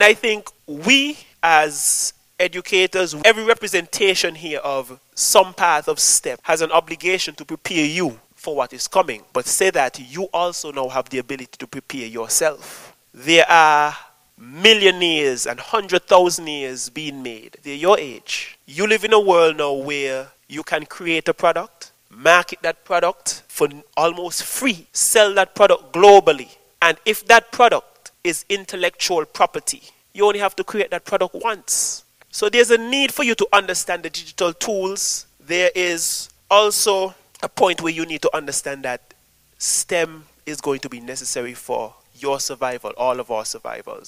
STEM application and vision on Nevis discussed during Science Symposium
The event included a panel of local and international STEM professionals along with a participating audience, discussing the advancements made in STEM in the education, agriculture, medical and biological research sectors.
Here is one panelist